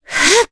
Cassandra-Vox_Casting2_jp.wav